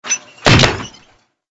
ENC_cogjump_to_side.ogg